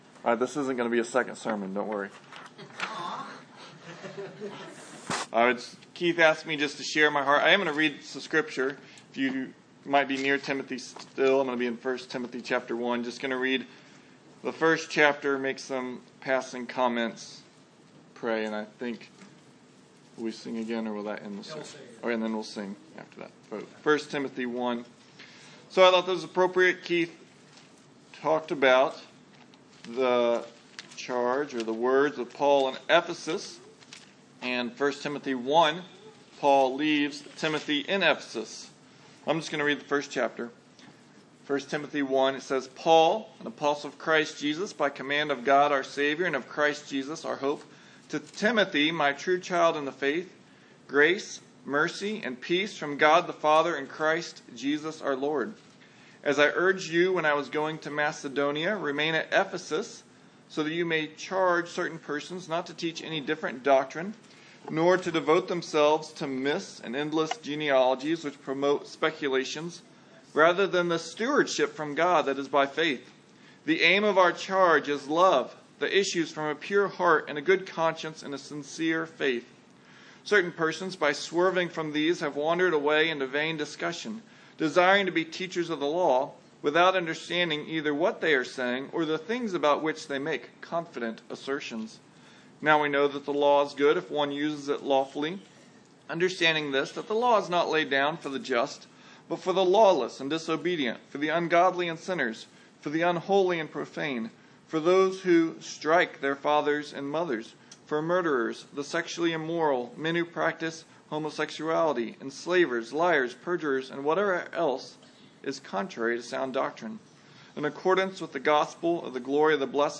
Sermon out of 1 Timothy